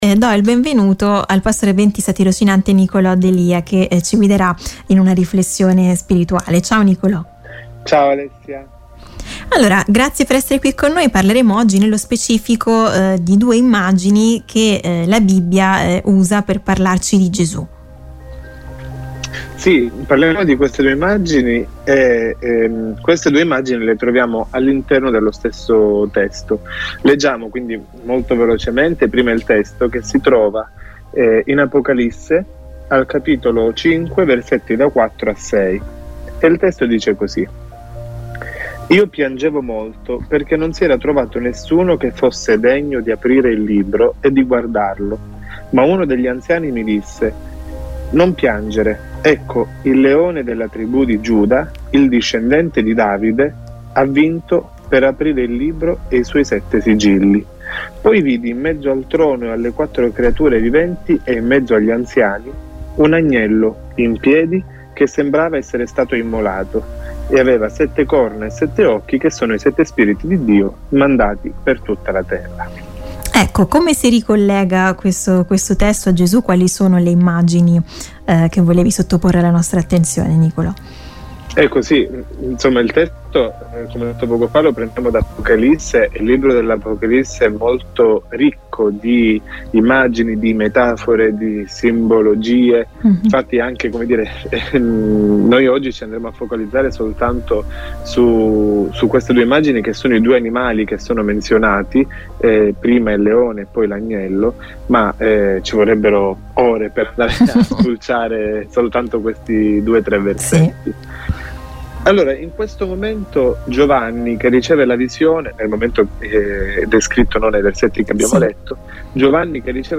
La Bibbia utilizza le immagini del leone e dell’Agnello per descrivere Gesù. Come è possibile che due animali così lontani e diversi tra loro possano rappresentare la stessa persona? Intervista